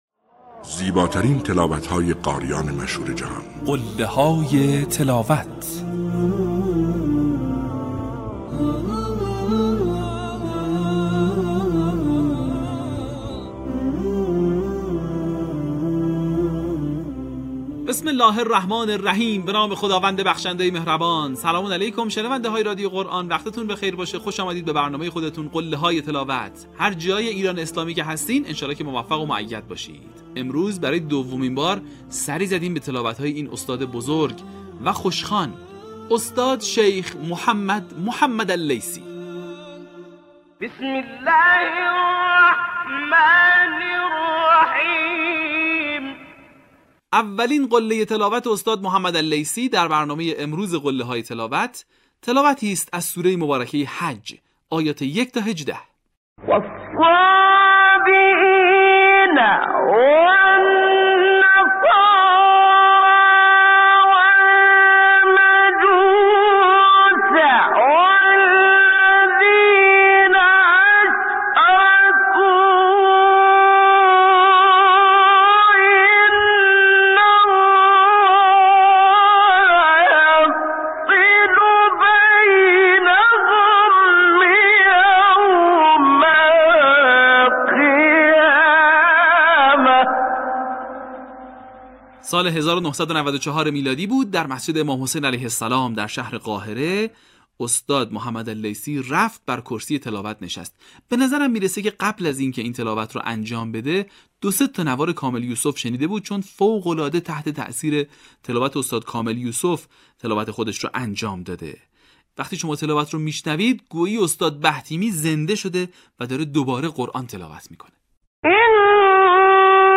این مجموعه شامل تلاوت‌های ماندگار قاریان بین‌المللی مصری است که تاکنون 40 قسمت آن از رسانه ایکنا منتشر شده است.